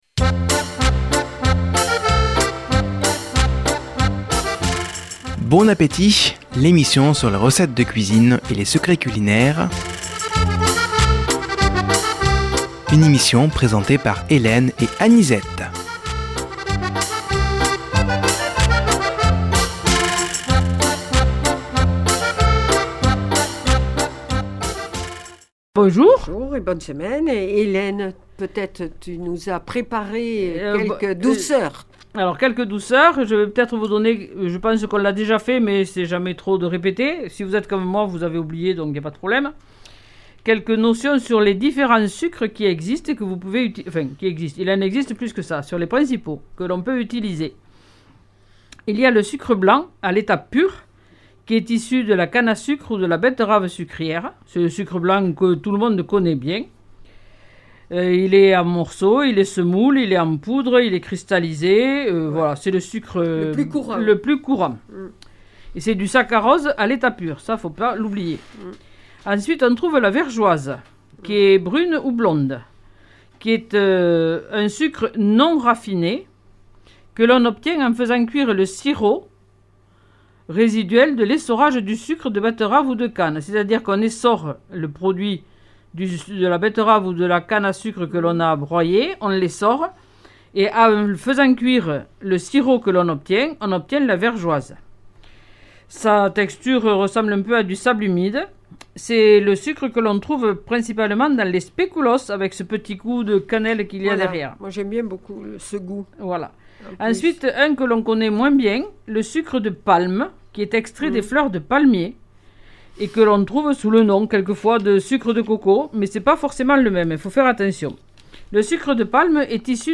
Nouvelle émission de cuisine avec aujourd'hui notamment des notions sur le sucre